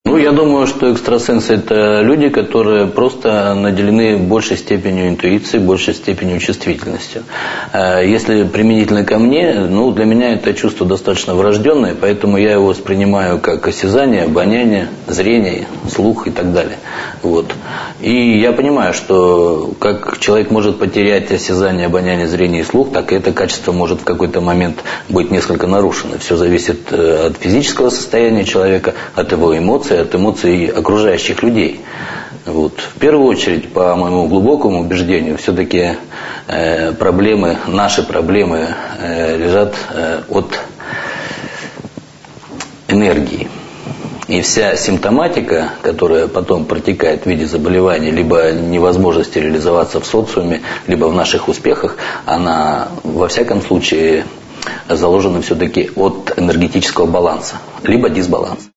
Аудиокнига: Экстрасенсы